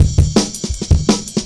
Heavy Ridez Cut 1.wav